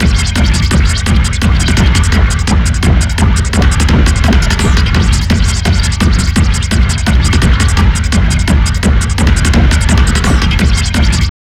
_ACID SEEMS 2.wav